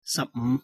sahpnǵh 15